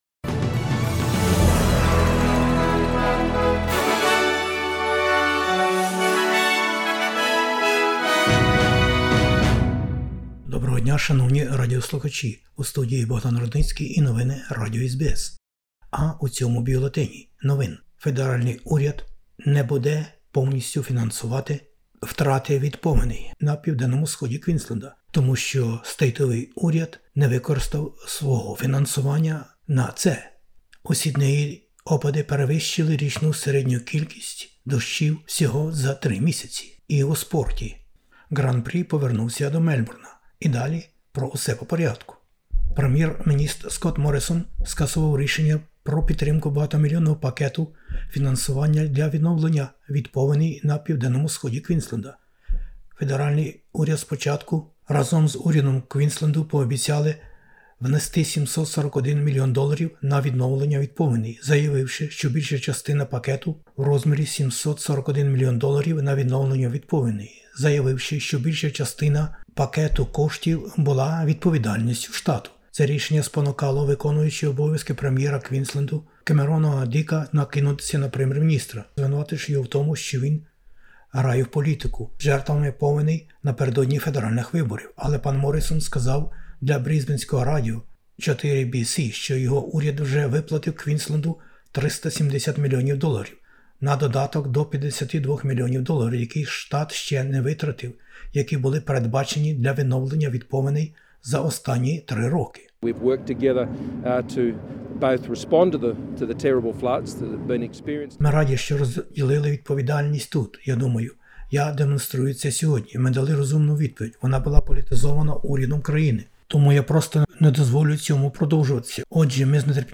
Бюлетень новин SBS українською мовою. Повені у НПВ і Квінсленді та різнобока політика навколо них. Лідер Федеральної опозиції Австралії про страхіття війни в Україні та про можливу висилку російських дипломатів із Австралії. Президент США про військові злочини в Україні.